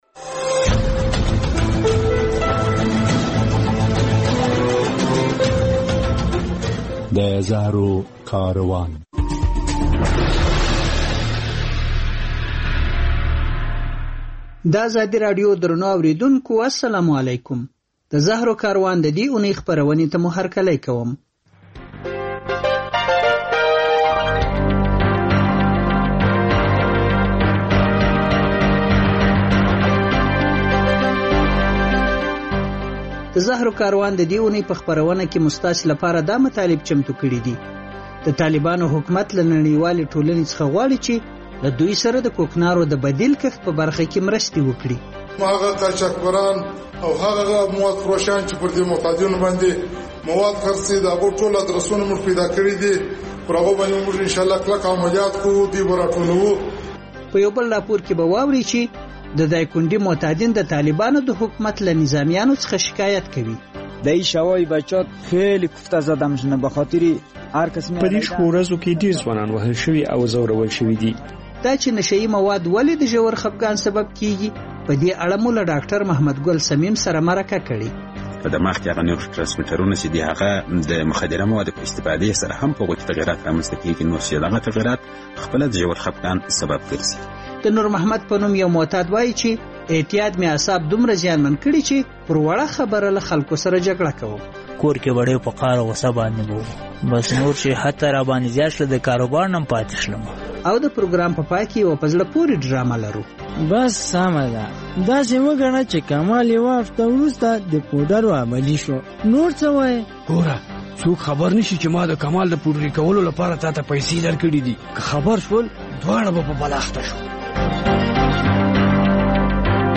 د زهرو کاروان په دې خپرونه کې اورو چې، د طالبانو حکومت له نړیوالې ټولنې غواړي چې د کوکنار د بدیل کښت او معتادینو د درملنې په برخه کې له دوی سره مرسته وکړي. له دایکندي ولایته په یور راپور کې اورو چې، معتادین د طالبانو د پوځیانو له چلنده شکایت کوي.